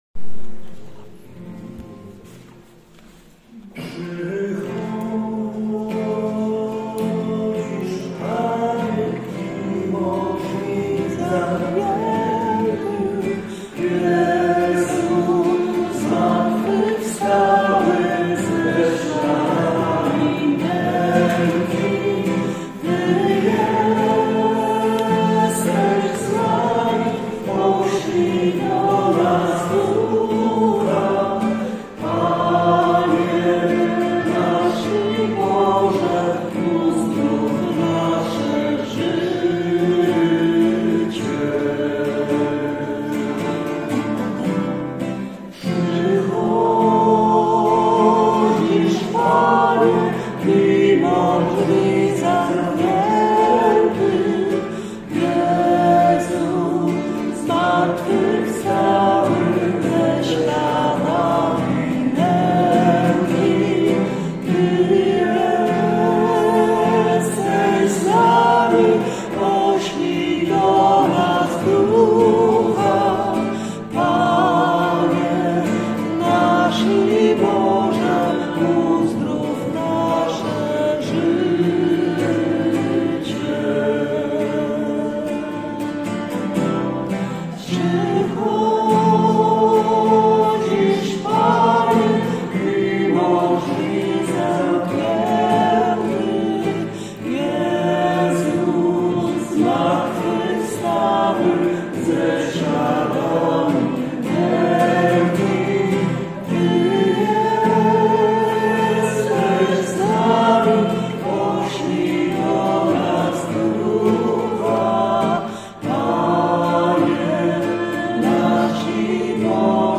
Spotykamy się w kaplicy Domu Parafialnego o godz. 10:30.